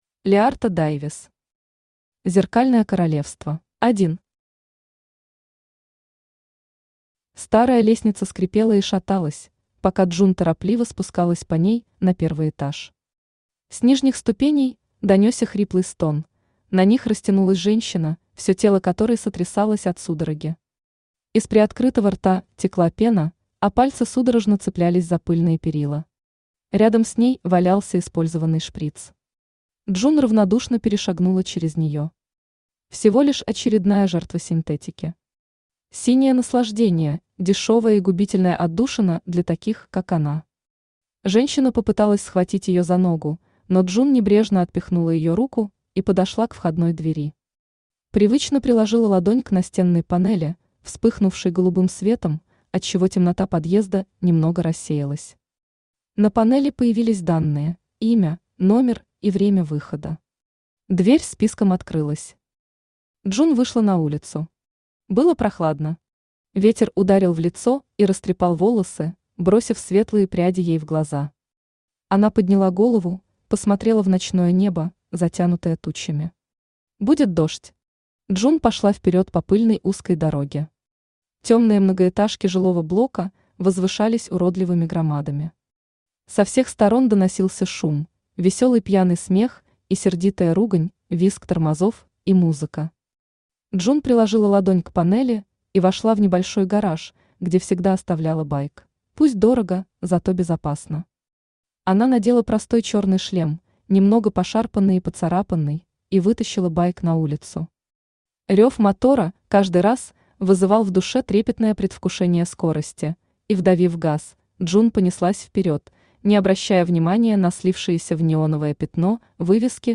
Аудиокнига Зеркальное королевство | Библиотека аудиокниг
Aудиокнига Зеркальное королевство Автор Лиарта Дайвис Читает аудиокнигу Авточтец ЛитРес.